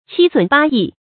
發音讀音
qī sǔn bā yì